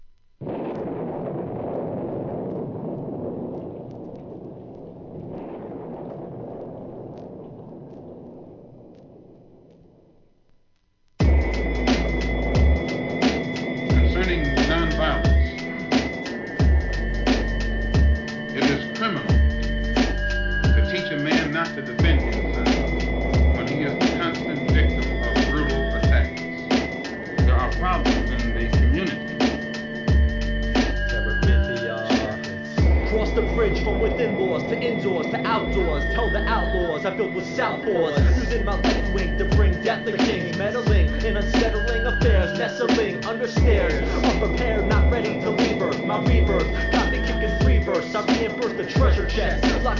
HIP HOP/R&B
2001年、N.Y.アンダーグラウンド!!